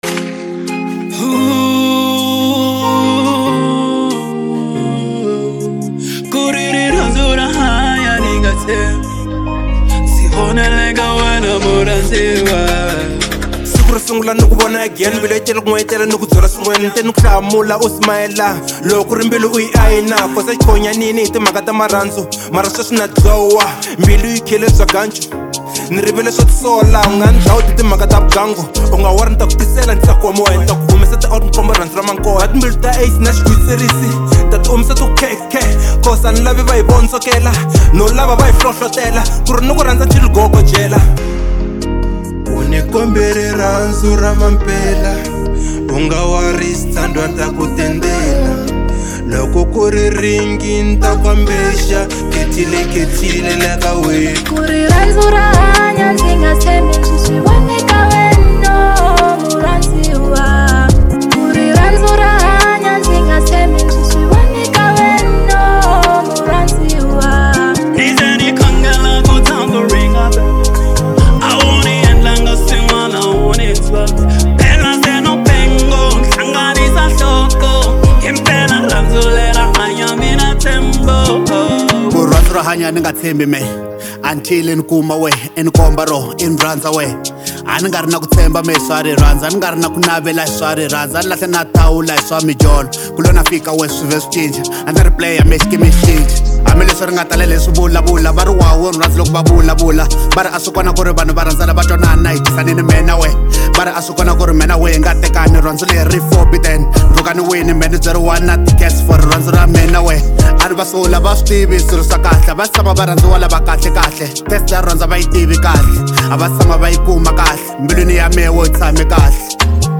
03:29 Genre : Hip Hop Size